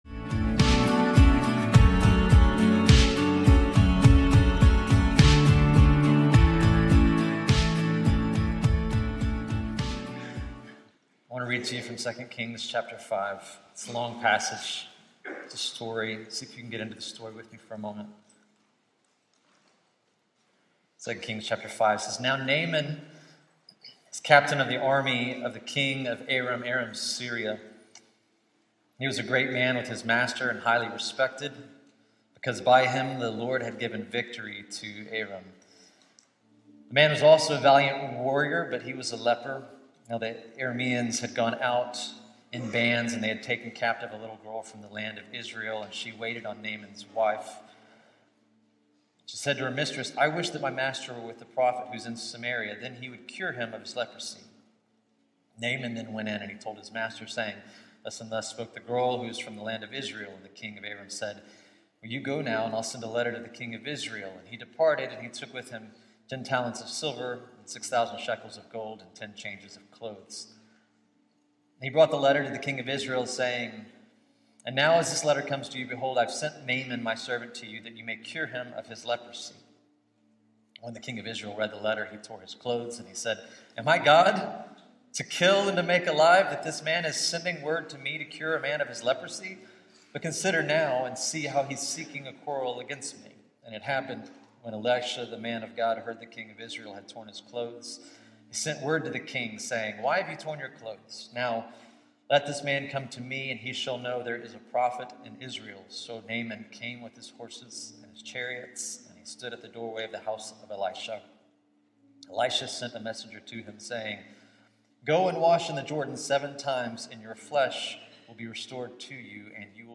What do you do when you can’t solve it—when grief, pain, or problems leave you at the end of your strength? In this deeply personal and timely sermon from 2 Kings 5, we follow the story of Naaman, a powerful warrior plagued by an incurable disease, and discover how God works transformation not through power or pride—but through humility, surrender, and unexpected obedience.
Standalone Sermon June 2 2024.mp3